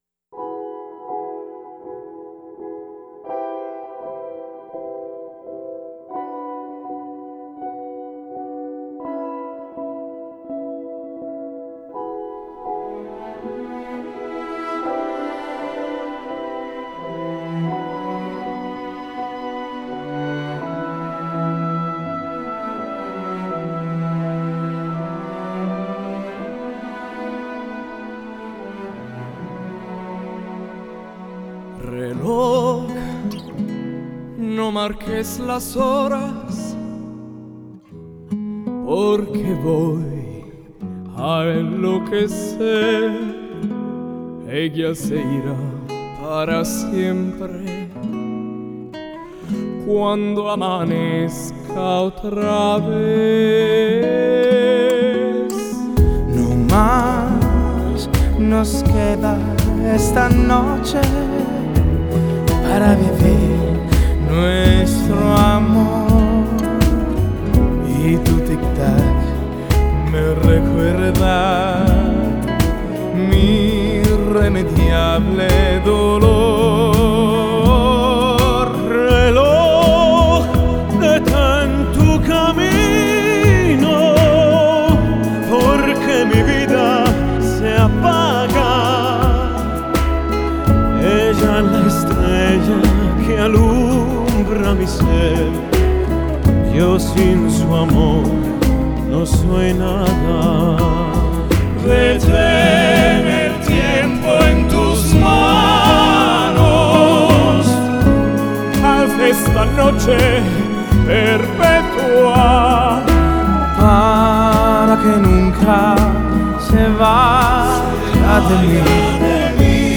новое явление на поприще жанра классикал кроссовер.